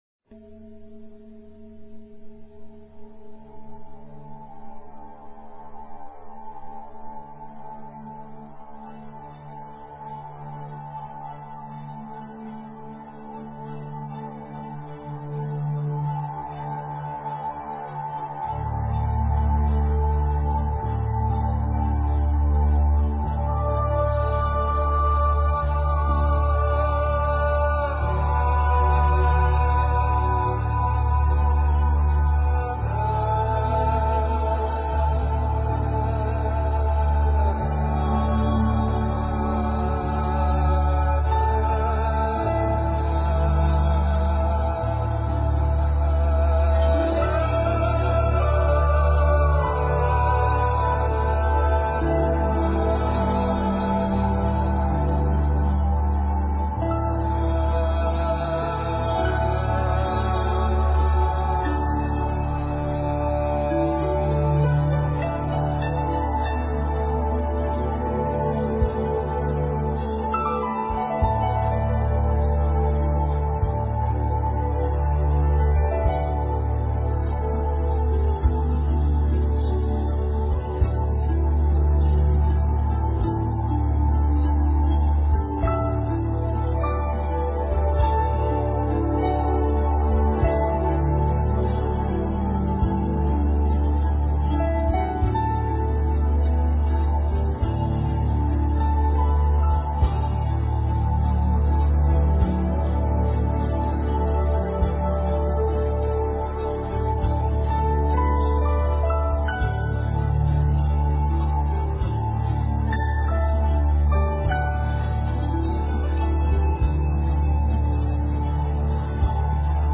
是 Be--禅定音乐 冥想 是 Be--禅定音乐 点我： 标签: 佛音 冥想 佛教音乐 返回列表 上一篇： 和谐 Harmony--禅定音乐 下一篇： 因果 Reason--禅定音乐 相关文章 32.禅的譬喻--佚名 32.禅的譬喻--佚名...